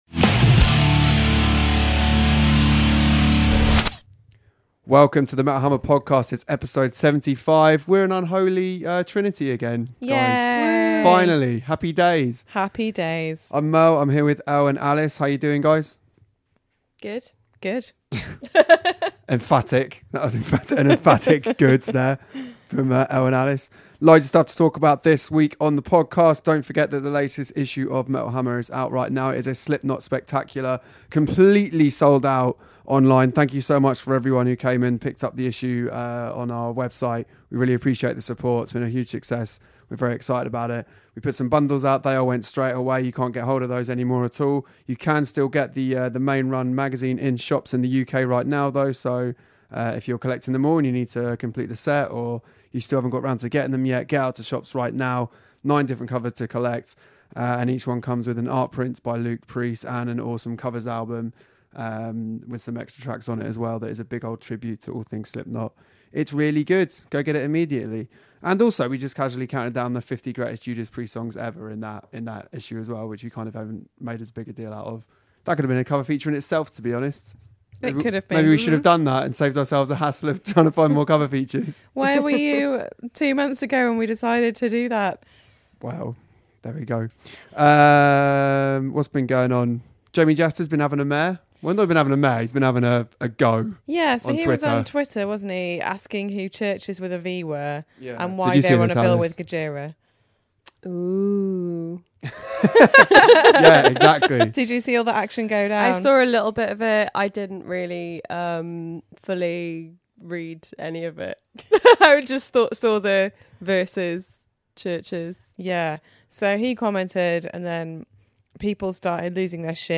all back in the studio!